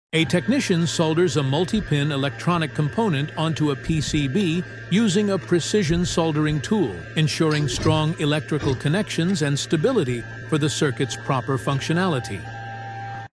This machine quickly and neatly solders the wires to the circuit board.